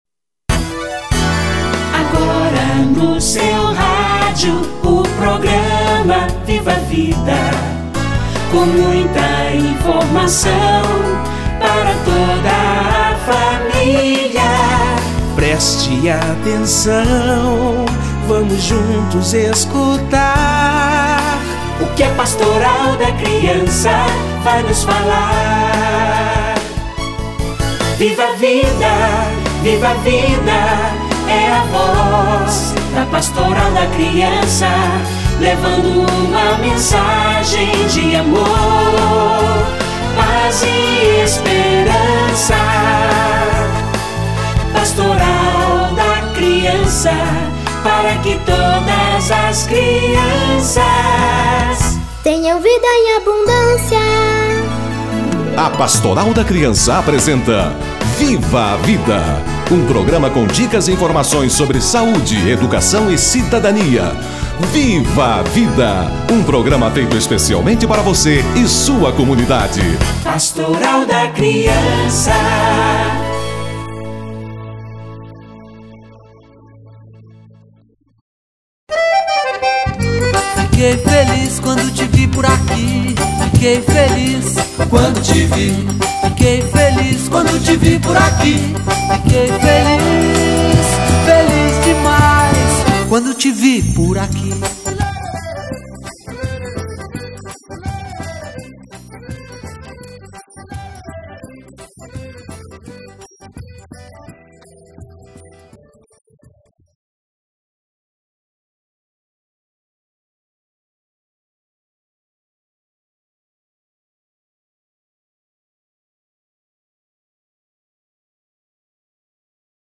Dengue e Chikungunya - Entrevista